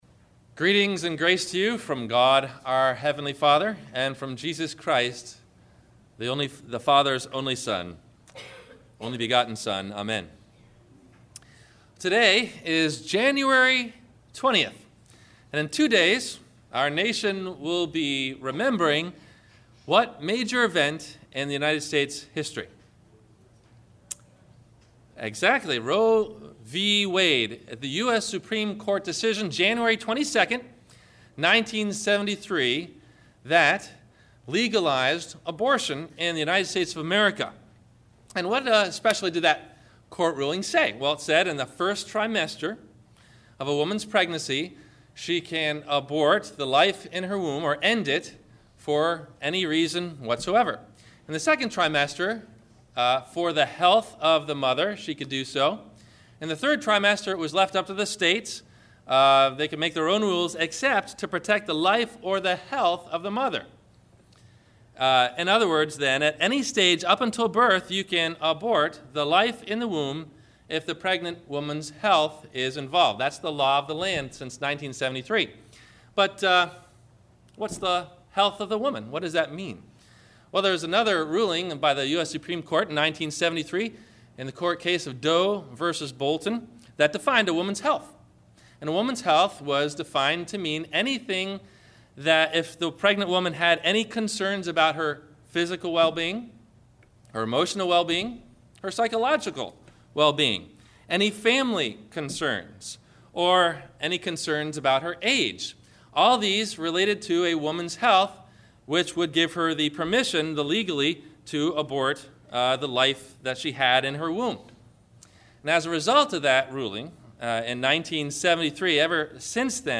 Life In The Womb - January 20 2013 Sermon - Christ Lutheran Cape Canaveral